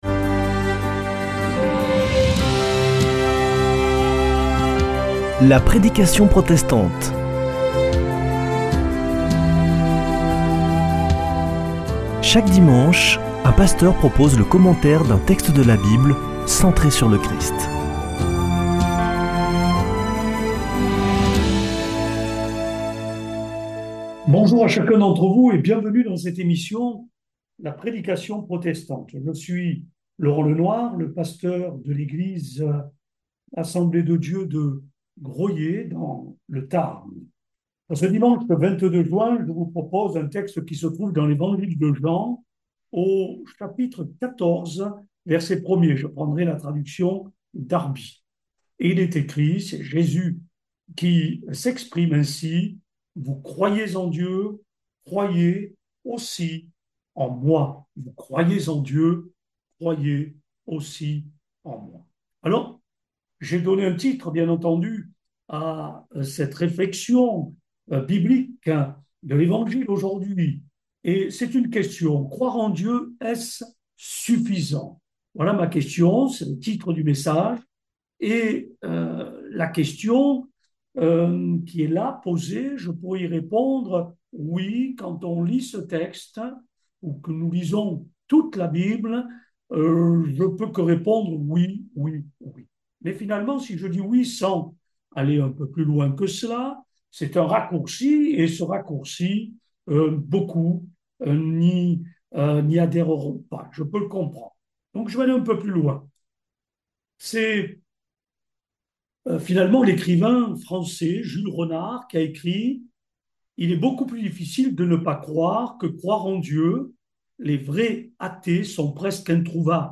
Accueil \ Emissions \ Foi \ Formation \ La prédication protestante \ Croire en Dieu est-ce suffisant ?